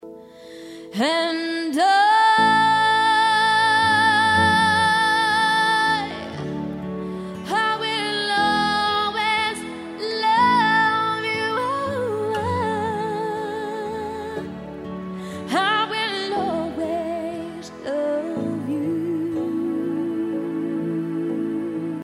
P  O  P     and    R  O  C  K      C  O  V  E  R  S